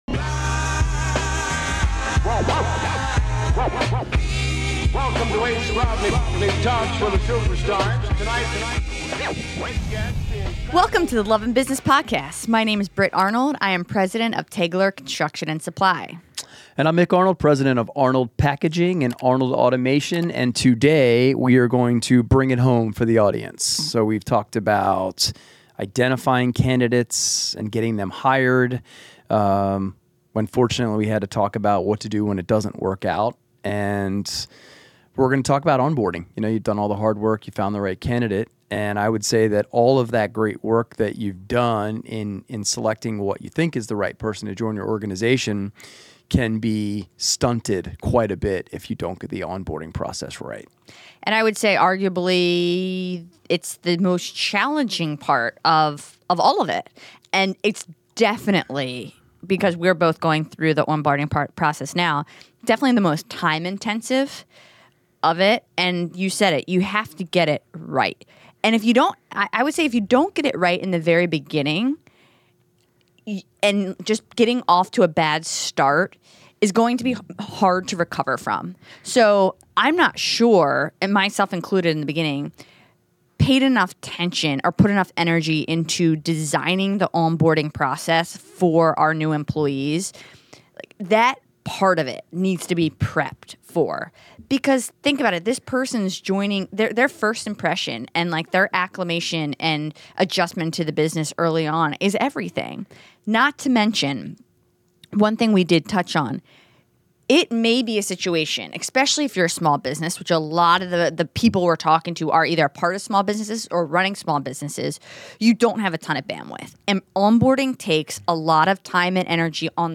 In this final episode of our CEO's Playbook series, we dive deep into the realities of onboarding new employees as business. Join us for a candid conversation where we share our personal experiences, challenges, and lessons learned from onboarding new team members.